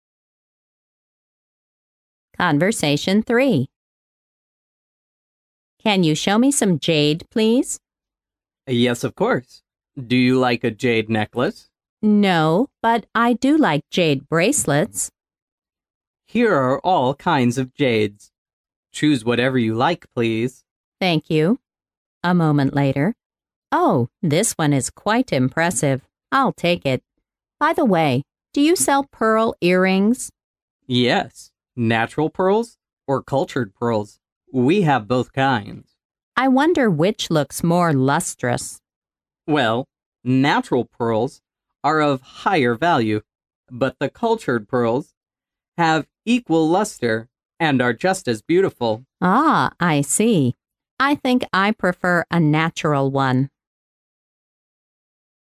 潮流英语情景对话张口就来Unit12：珍珠耳环mp3